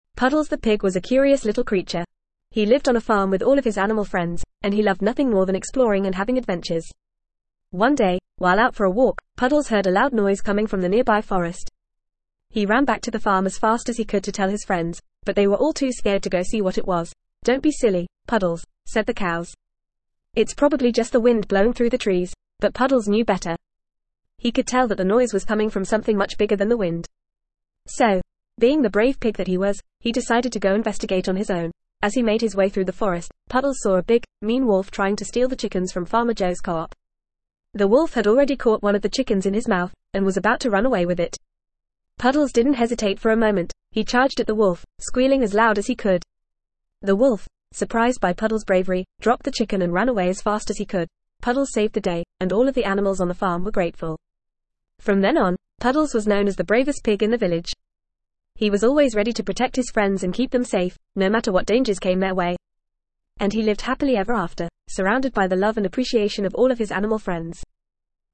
Fast
ESL-Short-Stories-for-Kids-FAST-reading-Puddles-the-Brave-Pig.mp3